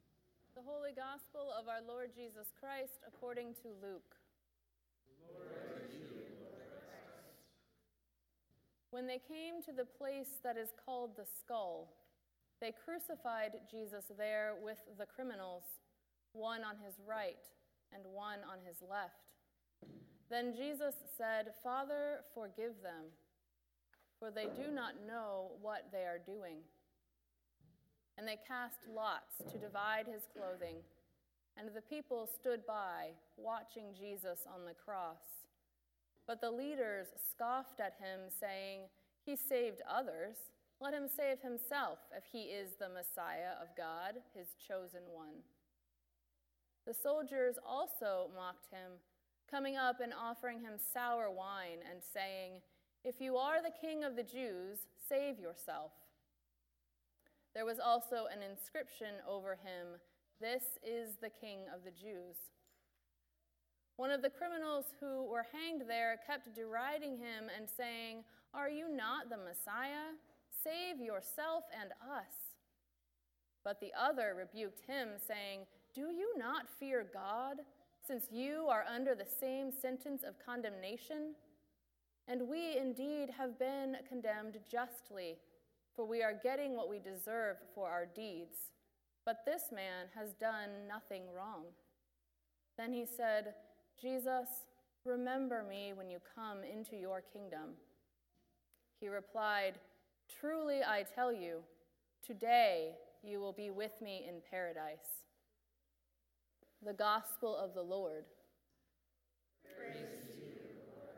Gospel Reading: Luke 23:33-43